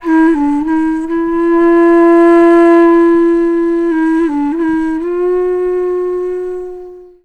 FLUTE-B01 -L.wav